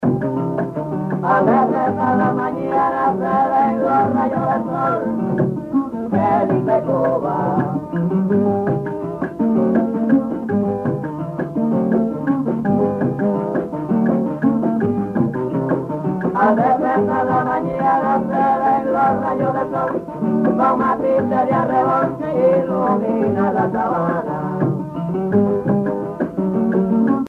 Parranda
Sancti Spiritus, Cuba
Pièce musicale inédite